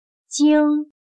京/jīng/Beijing